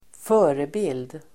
Uttal: [²f'ö:rebil:d]